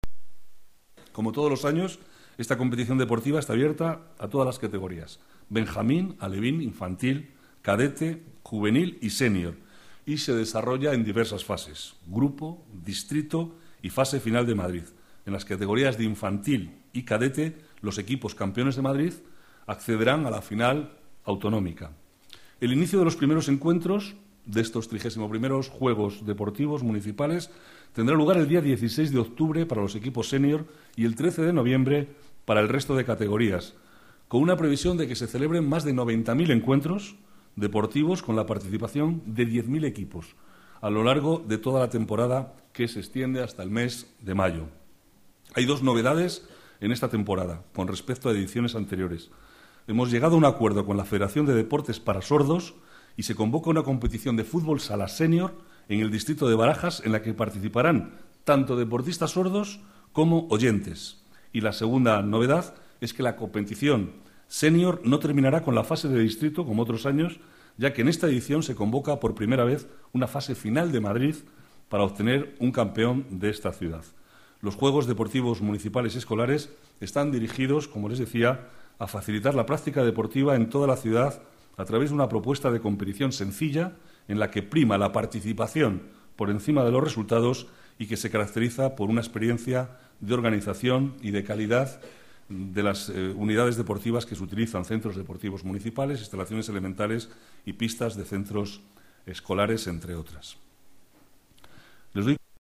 Nueva ventana:Declaraciones del vicealcalde, Manuel Cobo: Juegos Deportivos Municipales